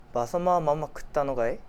Aizu Dialect Database
Type: Yes/no question
Final intonation: Rising
Location: Aizuwakamatsu/会津若松市
Sex: Male